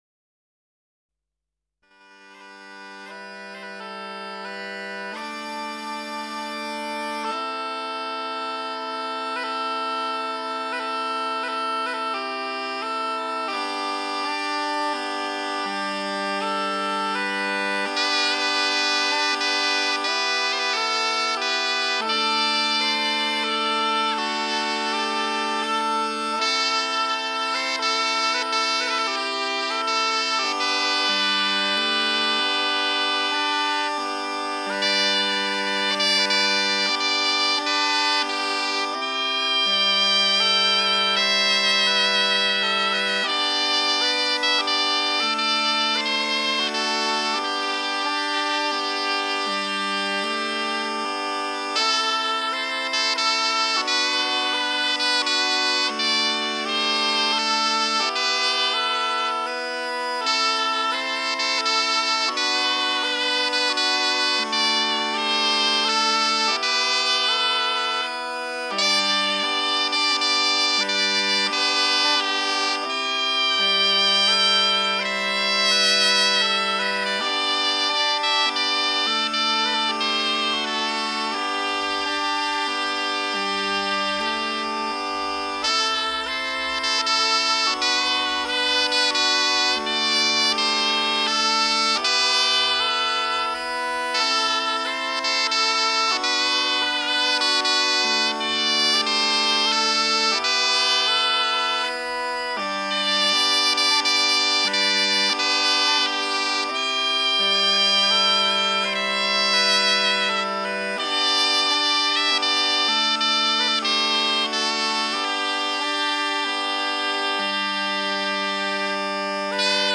La zampogna molisana
La zampogna emette un suono stridente e tremulo ed è, di solito, suonata all'aperto.